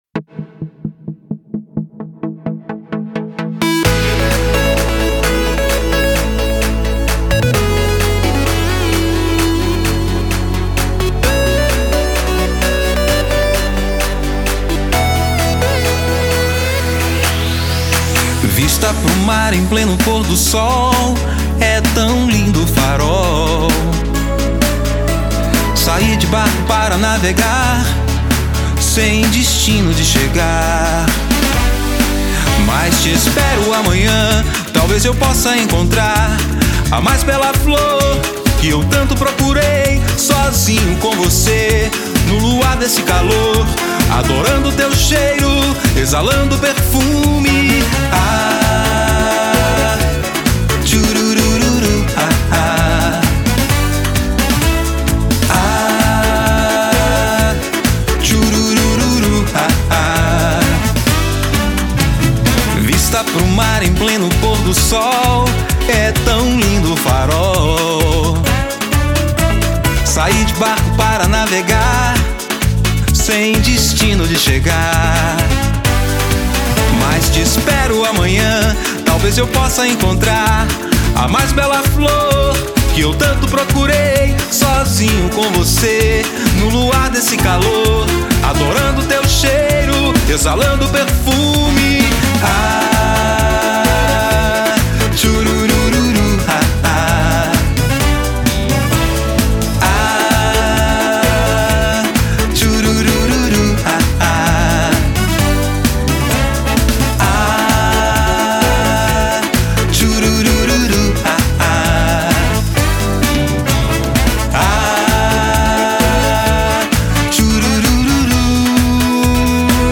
EstiloAxé